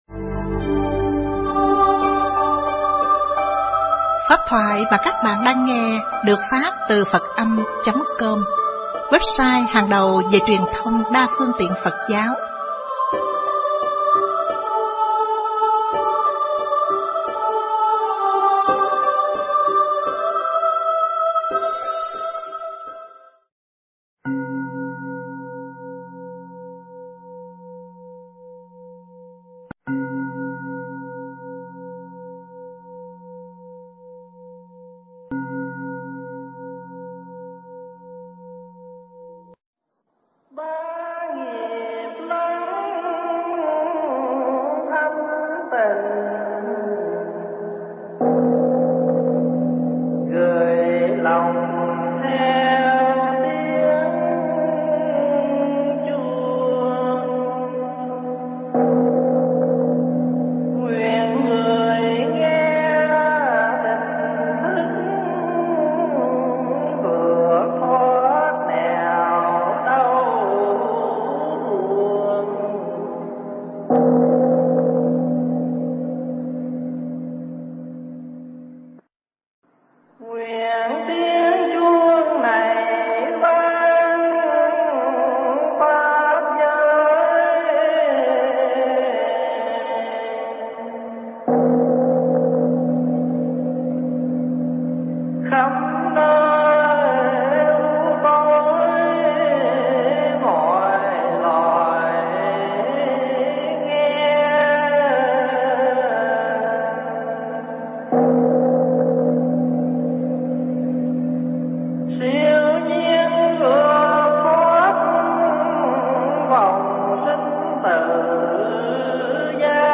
giảng tại Tu Viện Tây Thiên, Canada, trong khóa tu Xuất Gia Gieo Duyên